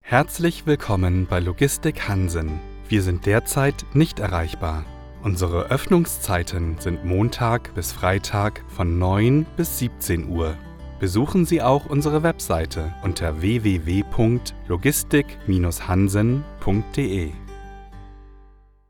Telephone